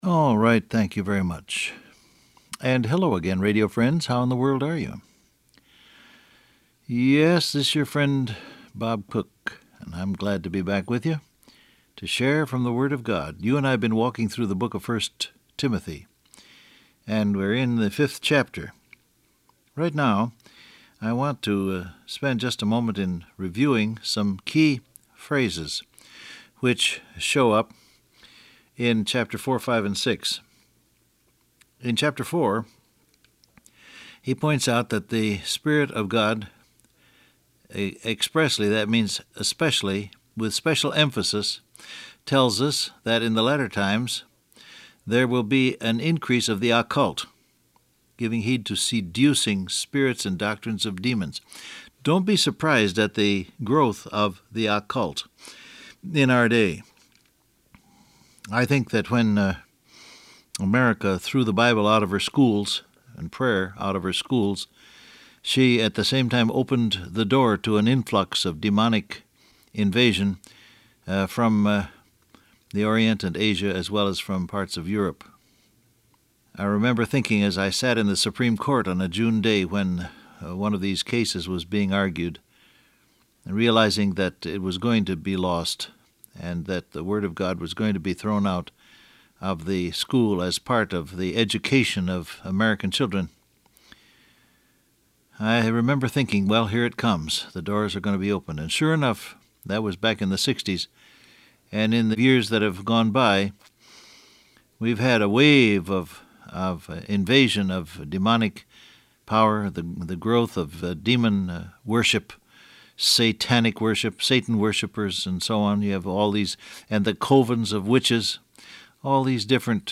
Download Audio Print Broadcast #6753 Scripture: 1 Timothy 4-5 Topics: Meditate , Money , New Birth , Remind , Observe Transcript Facebook Twitter WhatsApp Alright, thank you very much.